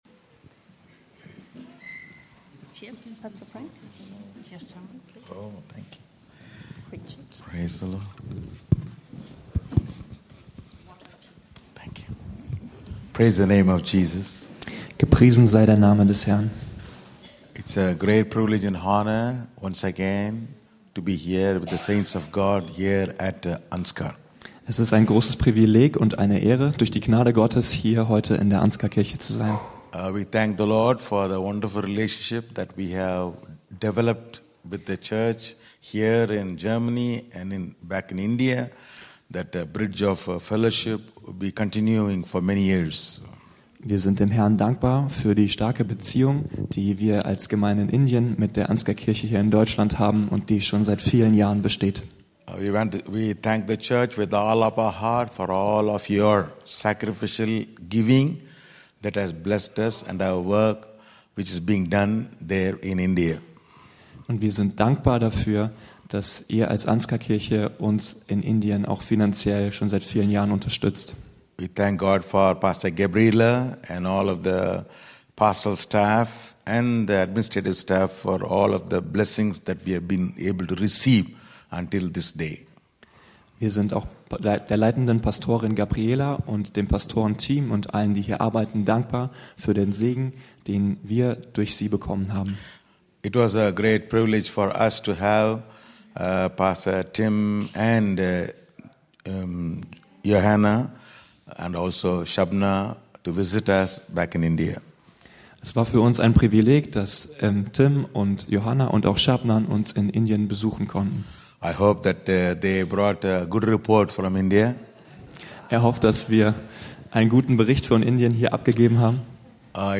Lets grow - Teil 4 Jakobus 5 ~ Anskar-Kirche Hamburg- Predigten Podcast